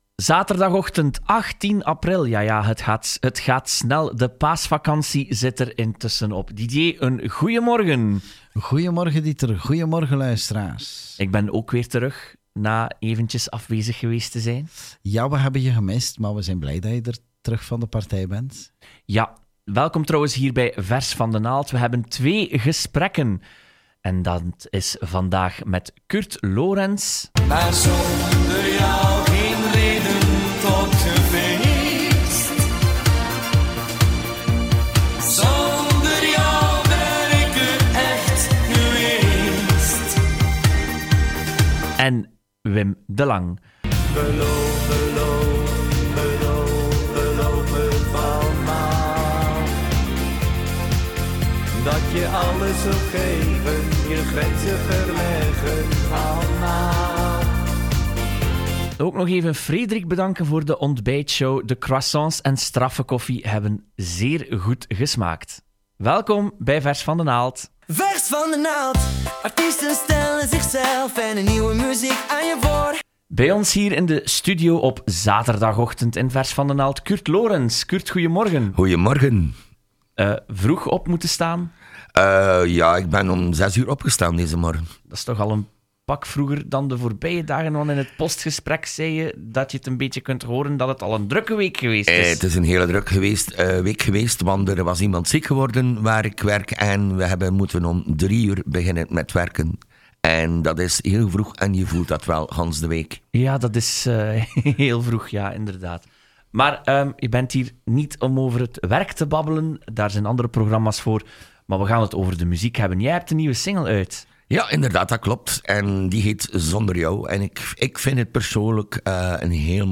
te gast in de VBRO studio.